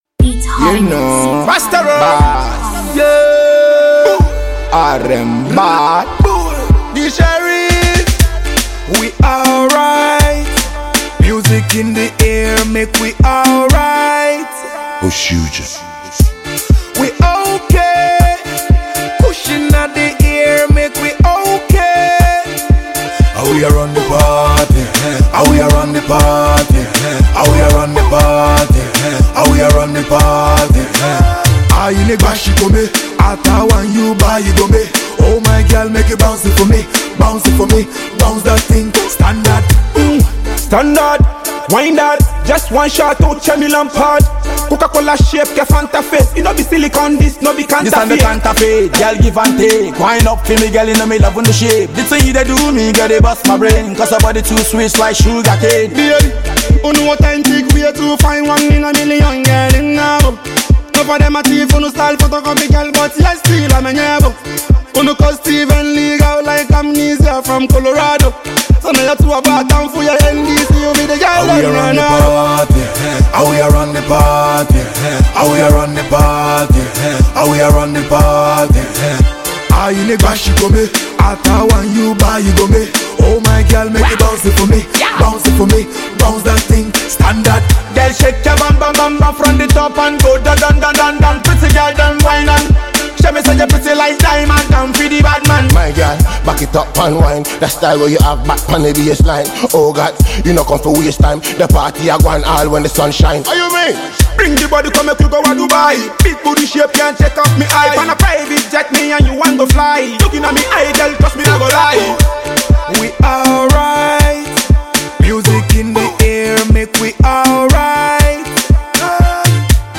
afro-dancehall collaboration